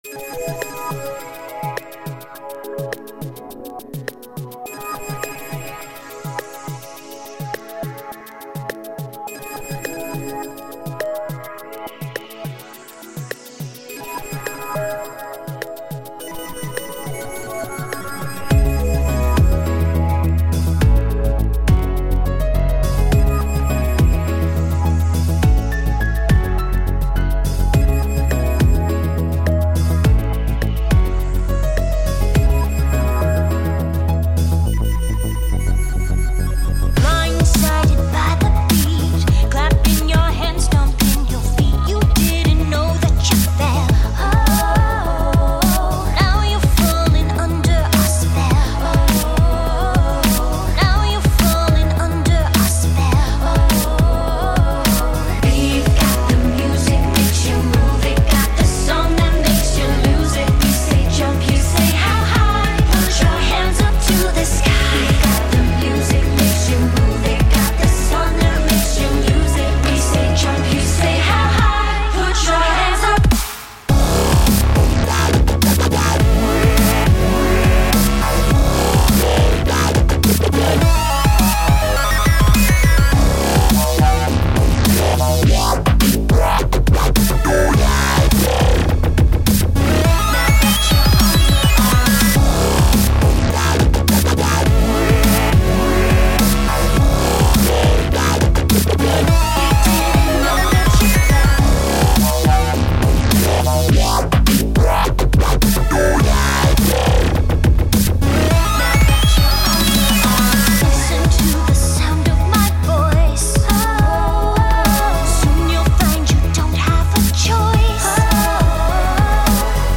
New remix for you guys!